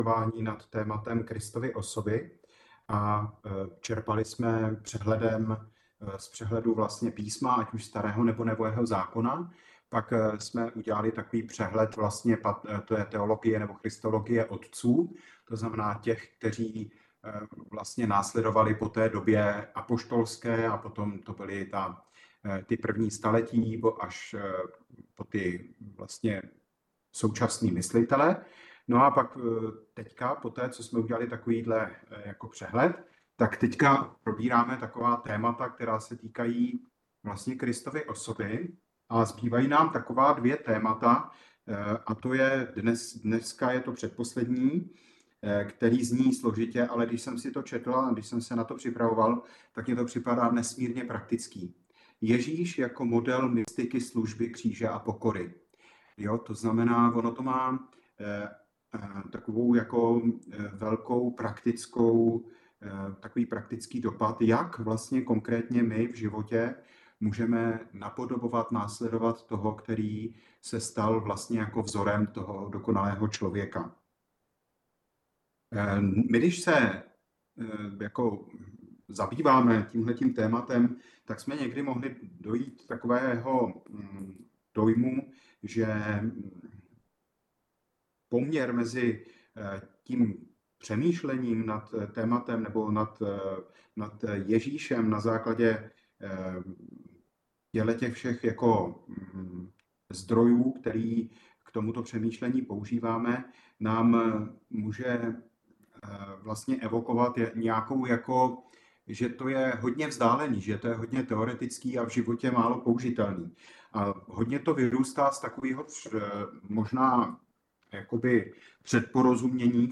Hodiny katechismu | Římskokatolická farnost u kostela sv.
Na této stránce najde zvukové záznamy našich on-line setkání — hodin katechismu.